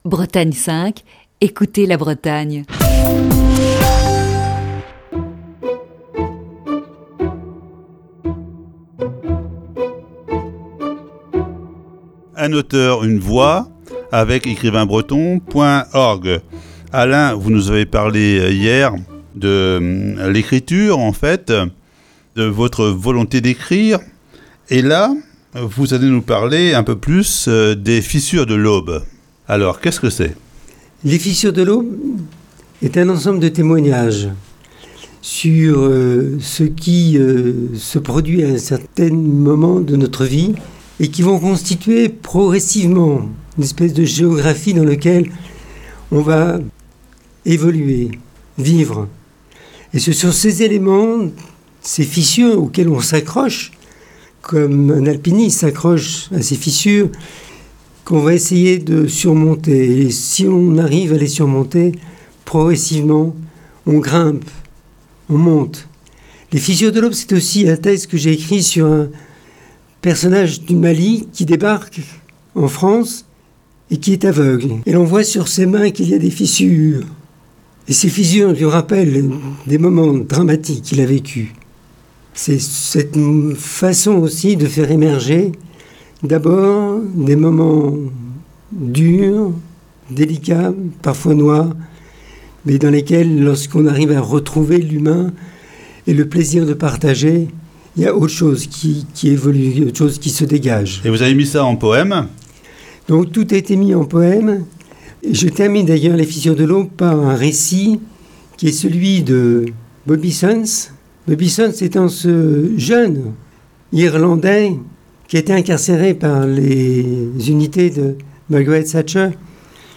Émission du 9 avril 2021.